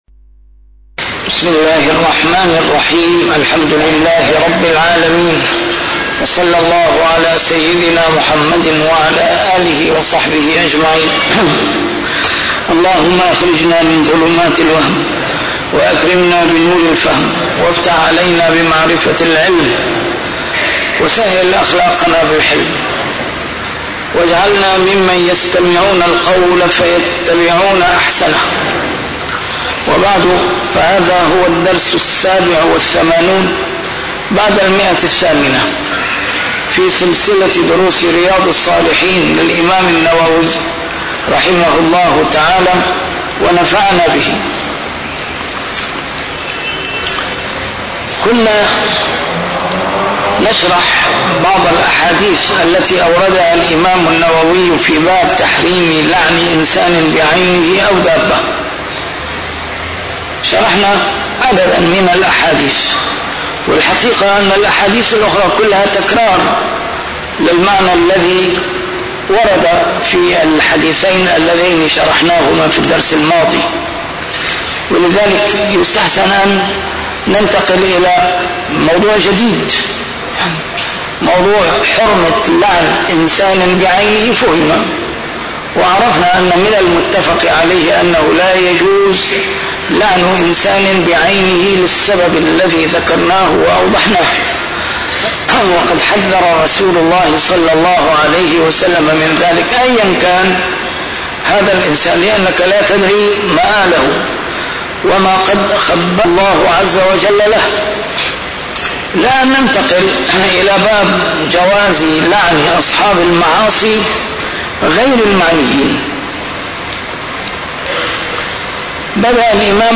A MARTYR SCHOLAR: IMAM MUHAMMAD SAEED RAMADAN AL-BOUTI - الدروس العلمية - شرح كتاب رياض الصالحين - 887- شرح كتاب رياض الصالحين: جواز لعن أصحاب المعاصي غير المعينين